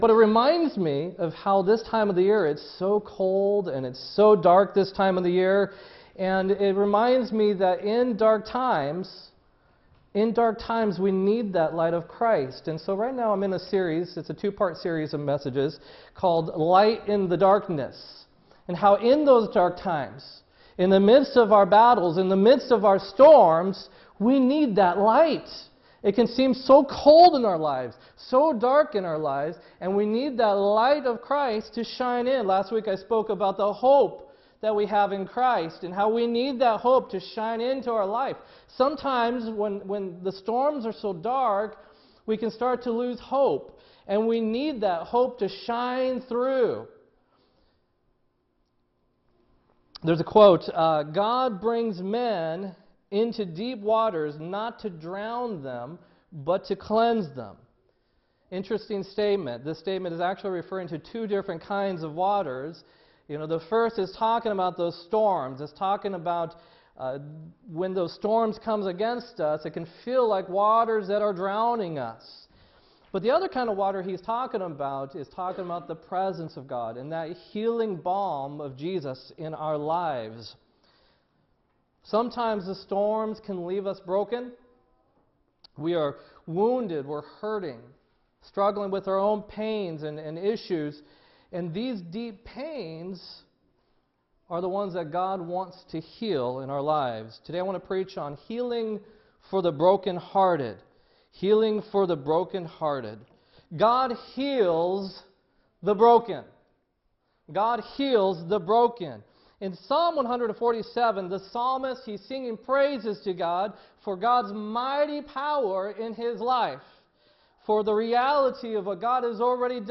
12-10-16-sermon